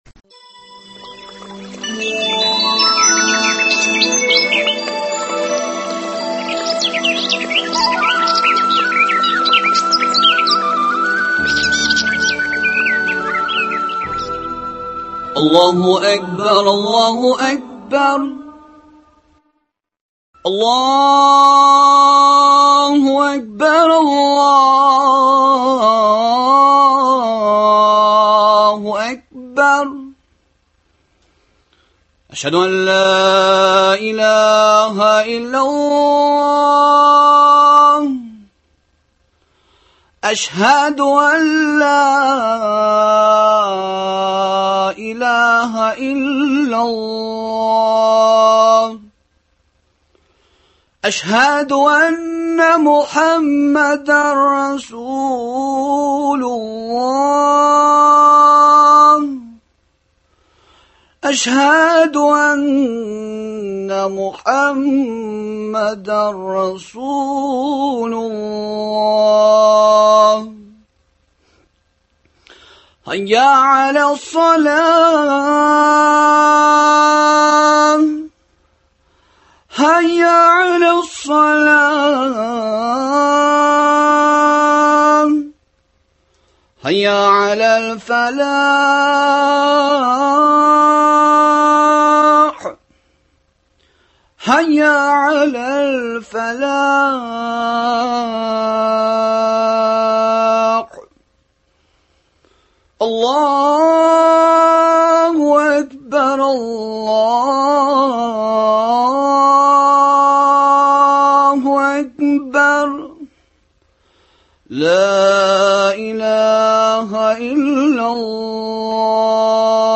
Коръәни Кәримнең Бакара сүрәсендәге 284, 285, 286 нчы аятьләренә аңлатма, аларны тормышта иркен куллану мөмкинлекләре бирә торган юллар турында сөйләшү. Дога кылуның үзенчәлекләре.